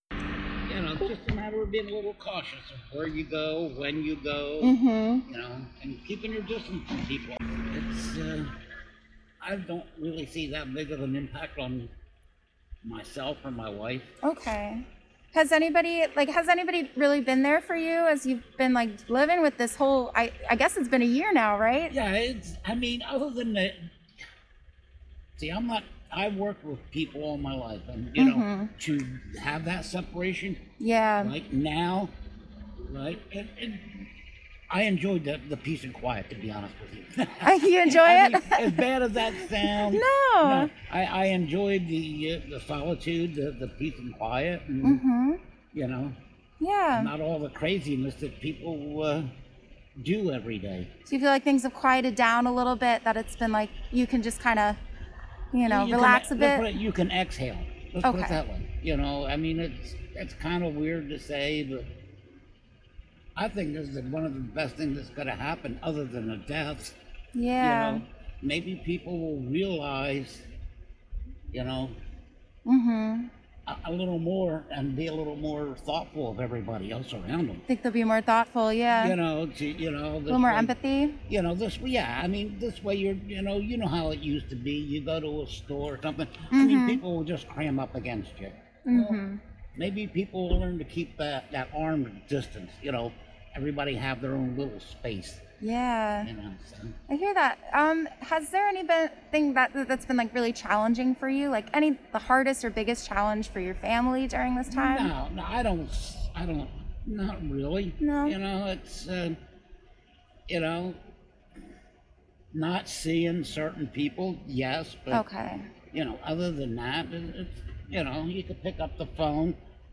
Participant 494 Community Conversations Interview